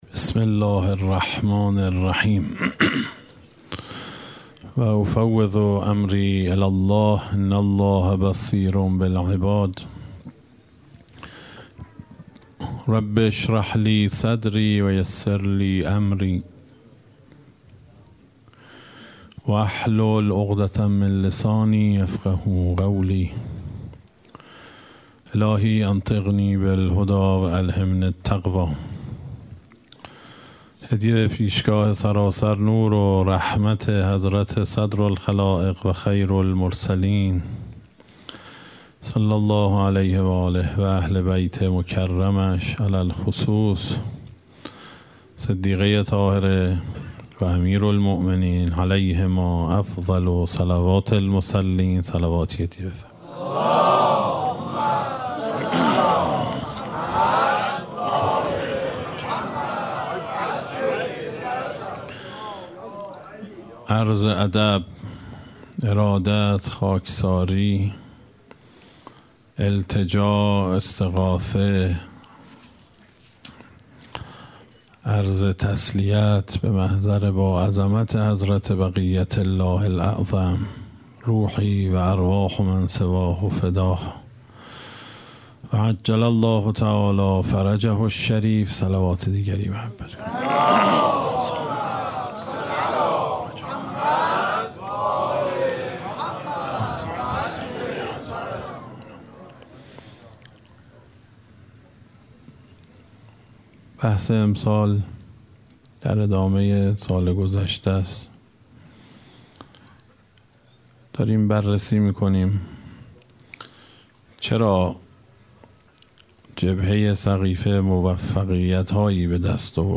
اشتراک گذاری دسته: الهیات شکست , حضرت فاطمه سلام الله علیها , سخنرانی ها قبلی قبلی الهیات شکست؛ فاطمیه اول – جلسه ششم از ده جلسه بعدی الهیات شکست؛ فاطمیه اول – جلسه هشتم از ده جلسه بعدی